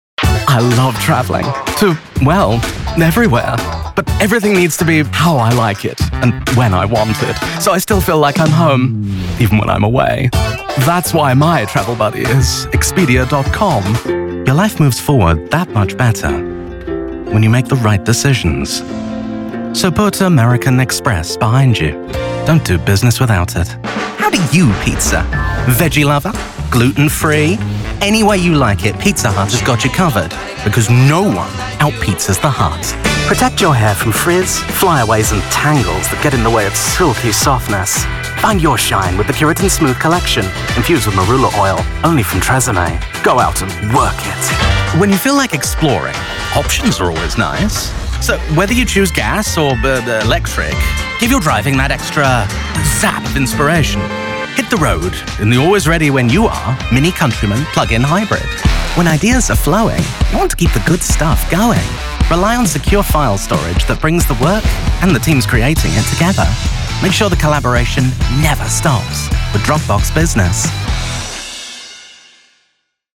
Commercials
As an actress, my commercial style is built on versatility, humour, and warmth. My unusual voice is tailor-made to make your commercial stand out... and adding a dose of my cheeky, friendly and sassy personality doesn't hurt either!
Demo